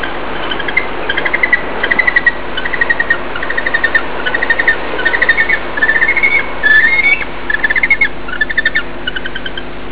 Yellow- Eyed Penguins
They have a sound file of the penguins here.
YEP_Call.au